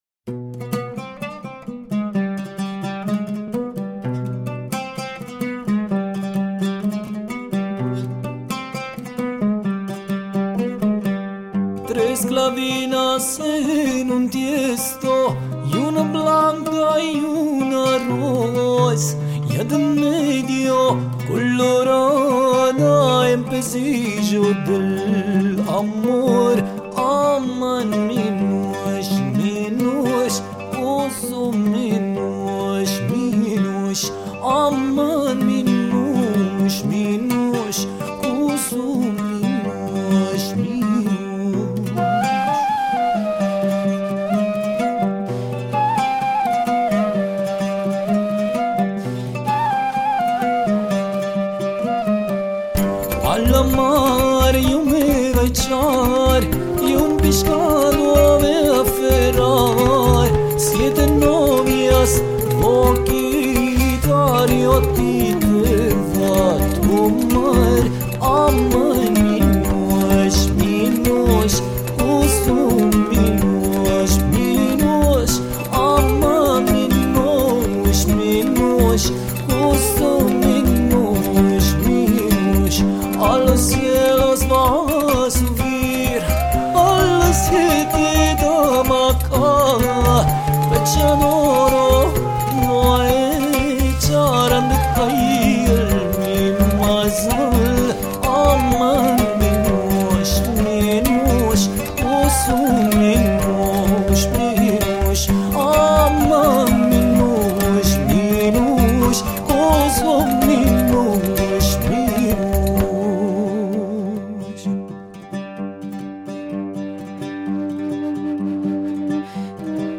voice, santur, guitar, shruti box, percussions